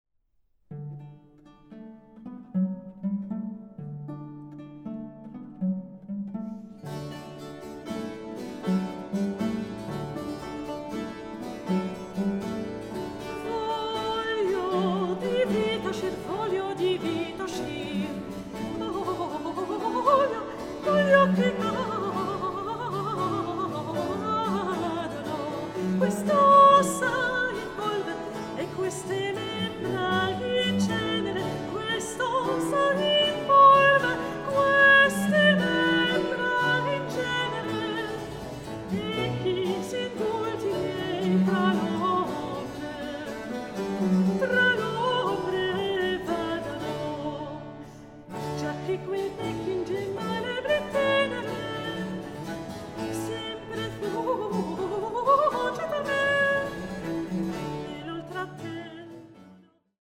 Baroque works on the theme of love in human and divine form
Soprano
The finely balanced ensemble and the agile, expressive voice